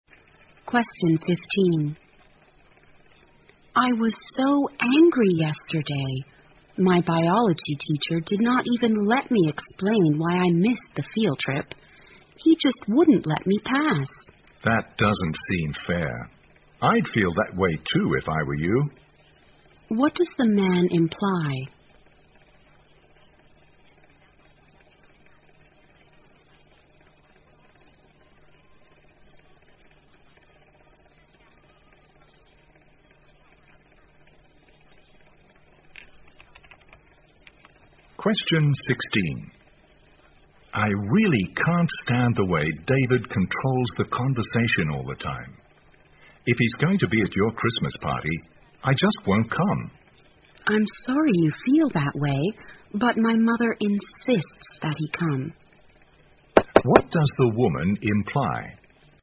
在线英语听力室034的听力文件下载,英语四级听力-短对话-在线英语听力室